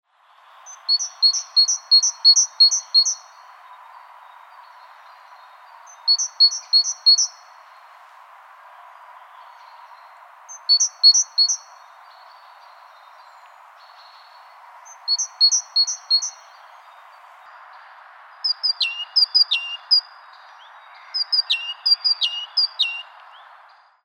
rasvatihane-eoy.mp3